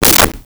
Whip 06
Whip 06.wav